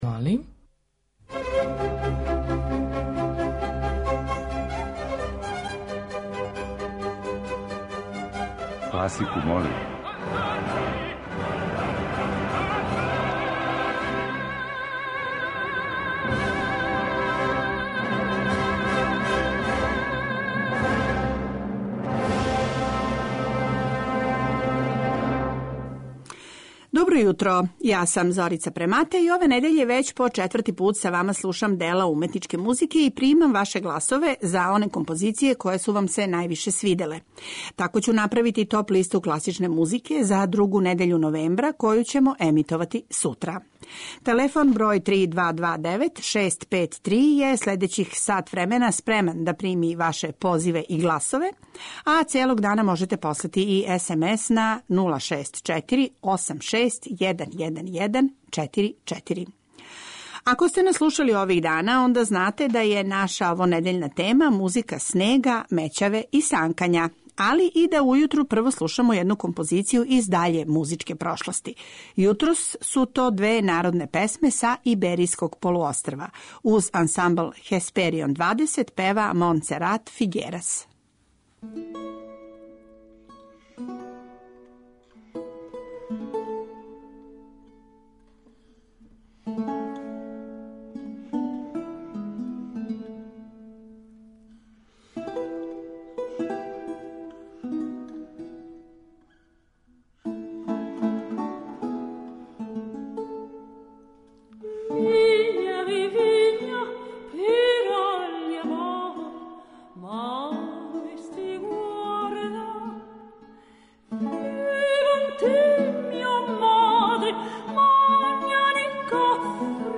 Између осталих, чућете неколико композиција посвећених санкању или снежним пејзажима, а из пера стваралаца разних стилова и епоха: Дилијуса, Дебисија, Свиридова, Прокофјева и Листа.
преузми : 21.31 MB Класику молим Autor: Група аутора Стилски и жанровски разноврсни циклус намењен и широком кругу слушалаца који од понедељка до четвртка гласају за топ листу недеље.